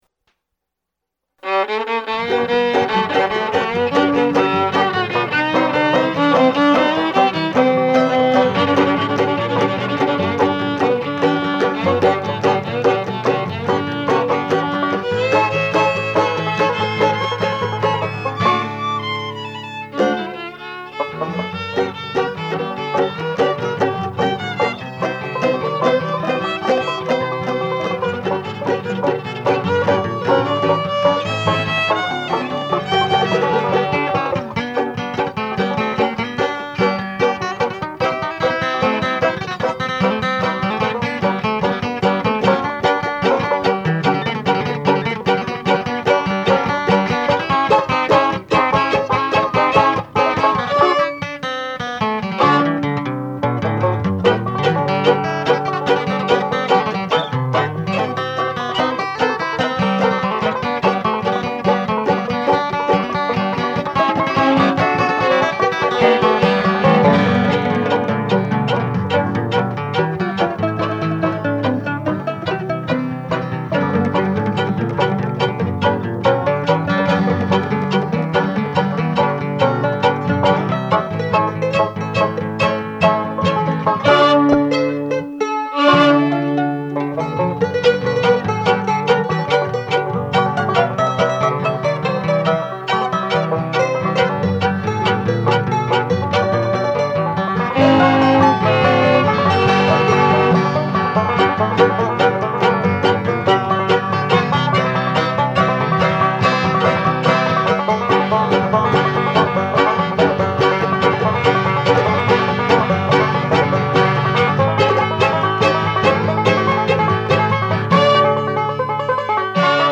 Banjo and Vocal
Guitar and Vocal
Violin
Mandolin